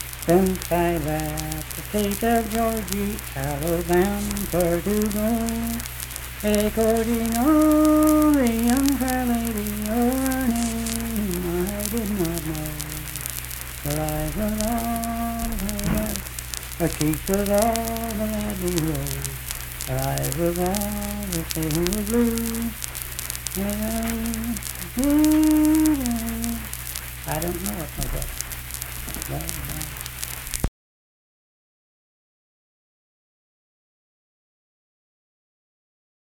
Unaccompanied vocal music performance
Verse-refrain 2(2-4).
Voice (sung)